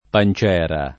pan©$ra] (meno com. pancera [id.]) s. f. — dim. pancerina — in antico, fino al ’500, sempre panziera [panZL$ra] (diversa dalla moderna non nella forma soltanto, essendo di maglie di ferro): Passò la corazzina e la panziera [paSS0 lla koraZZ&na e lla panZL$ra] (Luigi Pulci) — sim. i cogn. Panziera, -ri, Pancera, -ri, Panciera